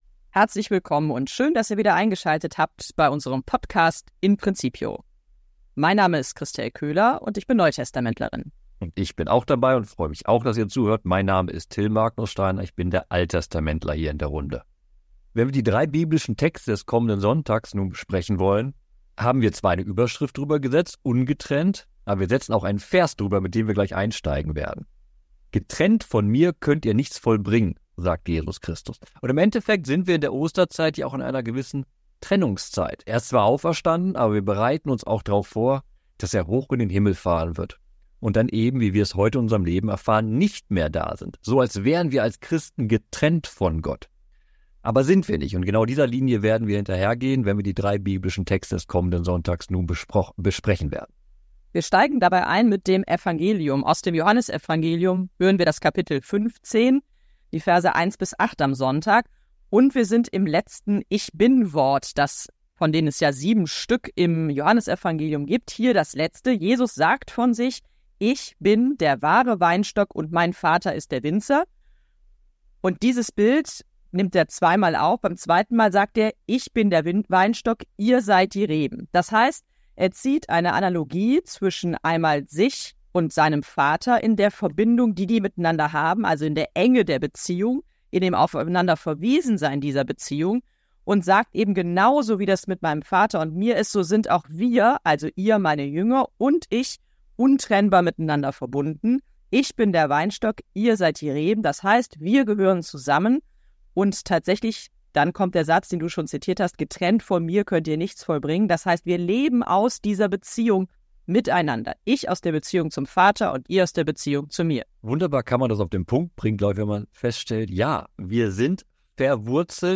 diskutieren über die biblischen Texte